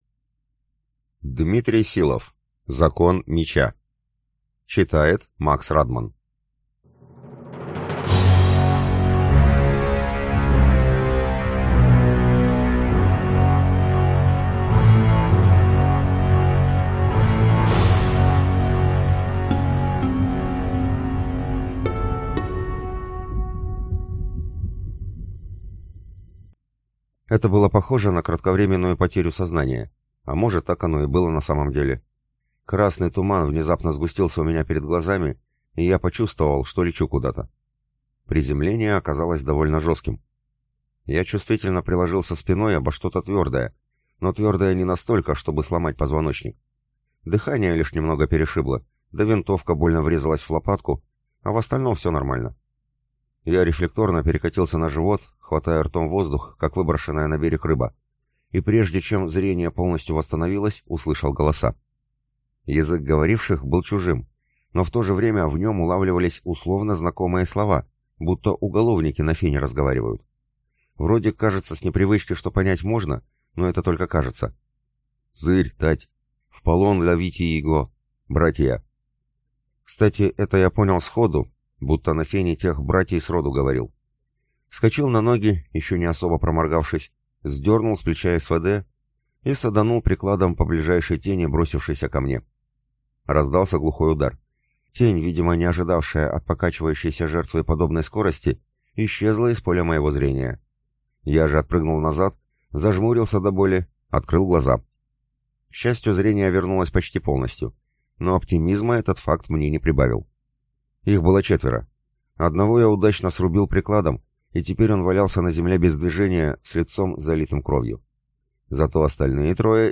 Аудиокнига Закон меча | Библиотека аудиокниг